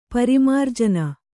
♪ parimārjana